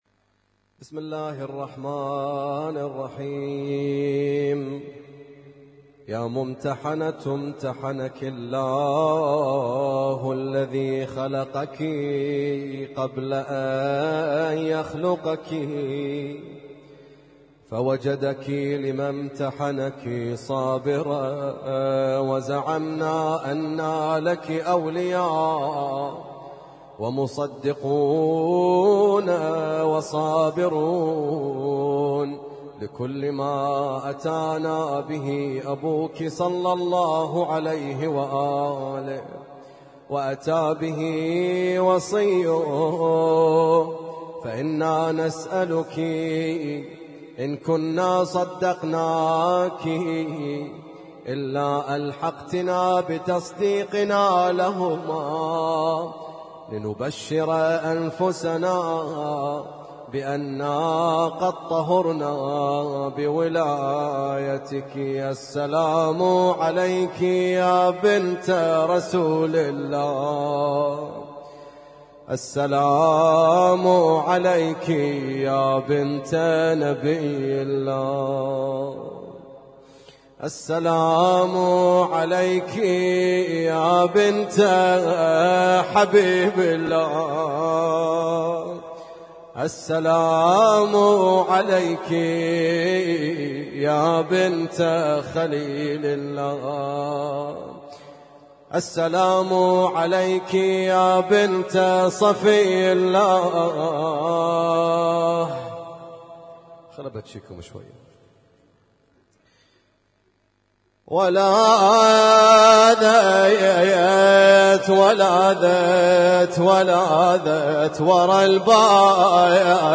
Husainyt Alnoor Rumaithiya Kuwait
زيارة فاطمة الزهراء عليها السلام - ليلة 10 جمادى الأولى 1442